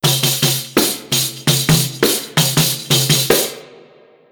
Danza árabe, golpear un pandero 03: a ritmo
pandereta
pandero
golpear